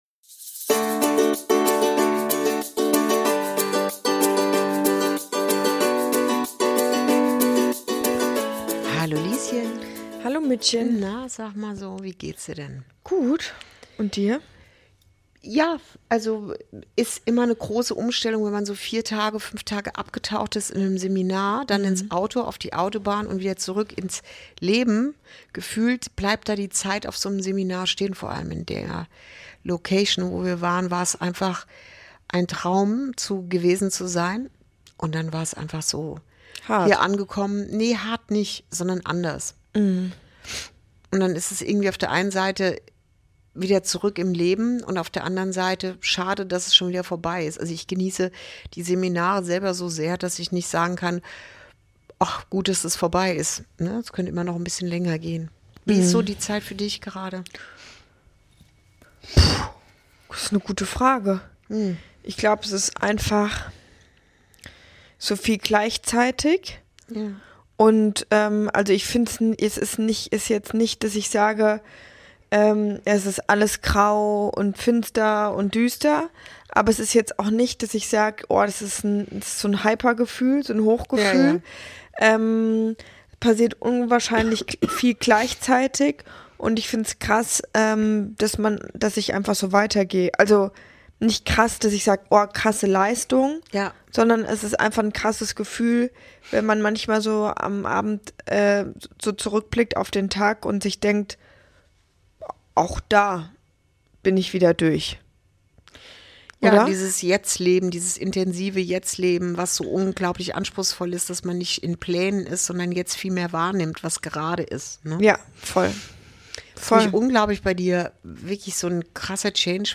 Folge 52: Zwischen Blutwerten und Bauchgefühl – dein Körper lügt nicht ~ Inside Out - Ein Gespräch zwischen Mutter und Tochter Podcast